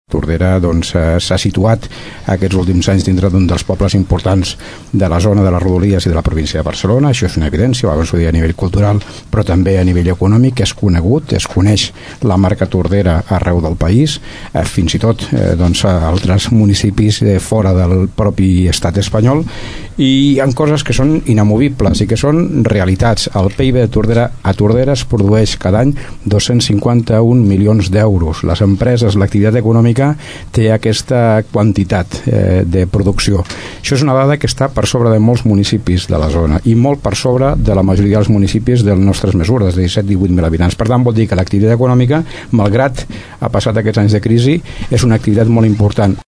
debat ciu
debat-ciu.mp3